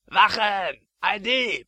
Deutsche Sprecher (m)
verschlagene Gestalt, kratzig Agressiv
VER - Dieb.mp3